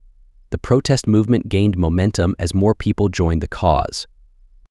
Play.ht-The-protest-movement-gained-momentum-as.wav